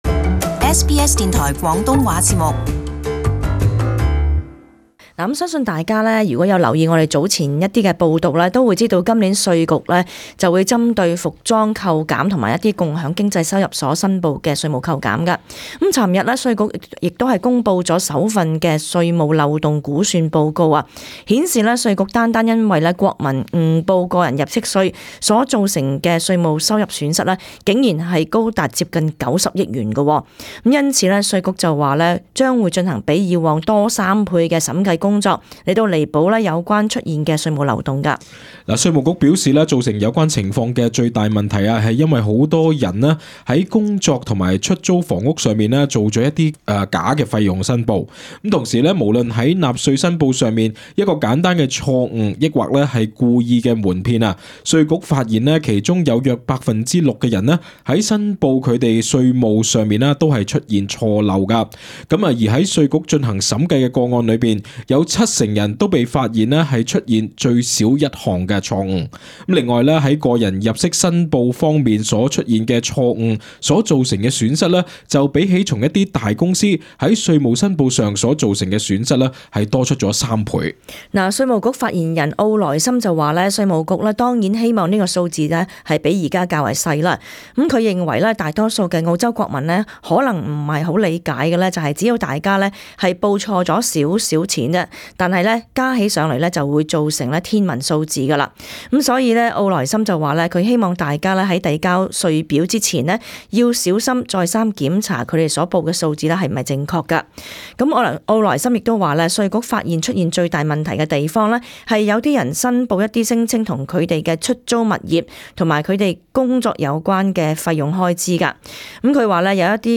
【時事報導】稅局將增加三倍審計工作彌補稅務漏洞